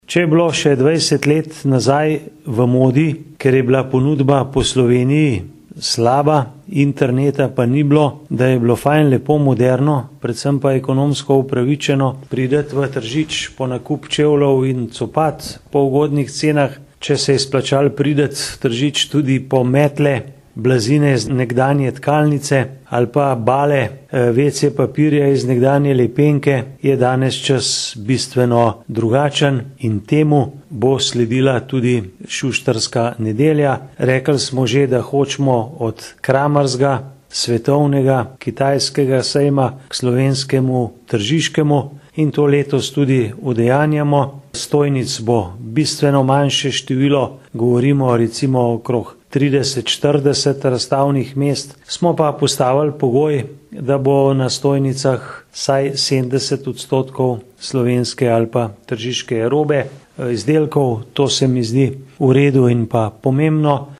izjava_mag.borutsajoviczupanobcinetrzic_sustarska.mp3 (1,5MB)